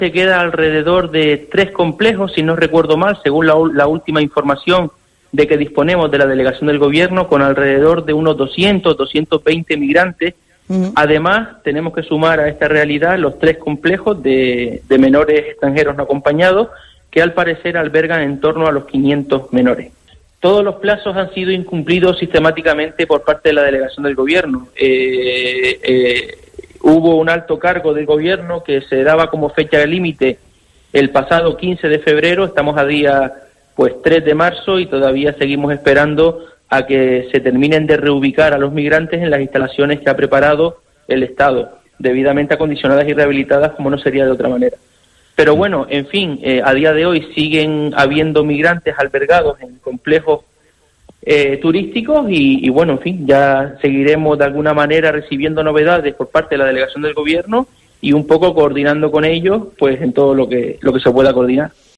Mencey Navarro, concejal de seguridad en el Ayuntamiento de Mogán